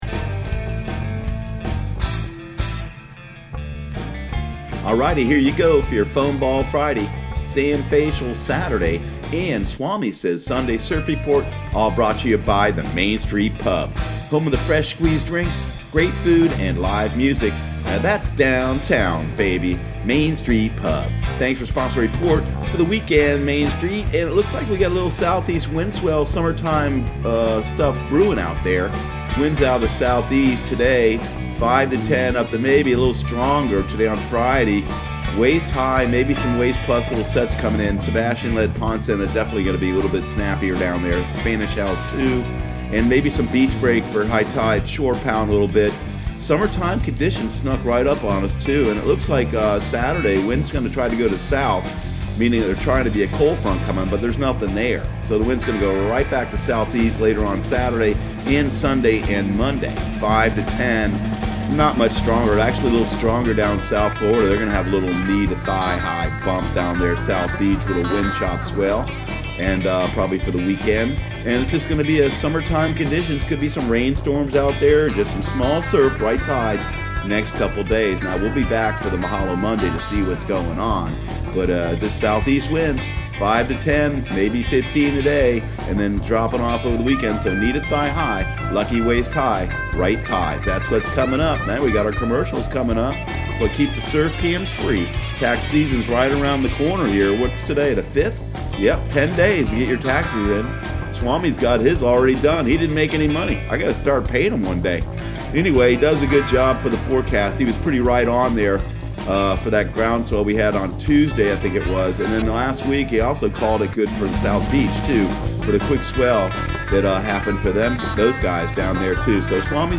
Surf Guru Surf Report and Forecast 04/05/2019 Audio surf report and surf forecast on April 05 for Central Florida and the Southeast.